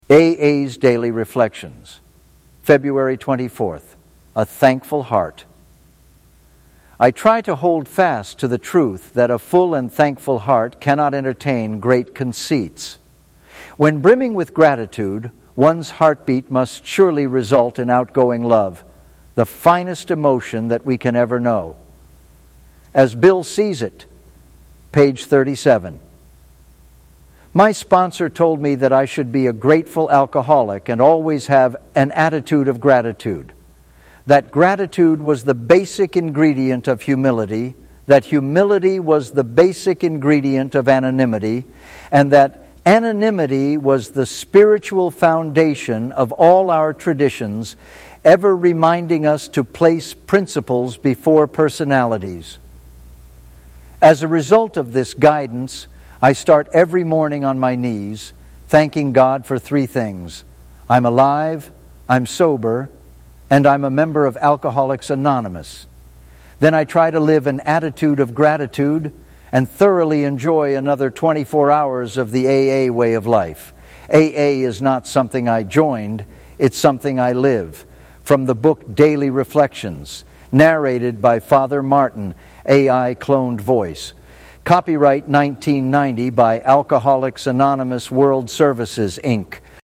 A.I. Cloned Voice